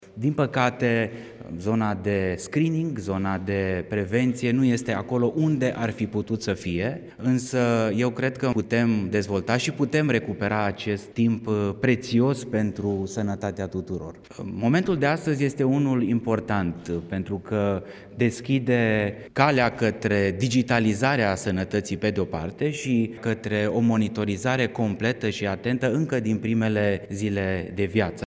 La rândul său, ministrul Sănătății, Alexandru Rogobete, a declarat că, la capitolul prevenție, mai sunt multe de făcut.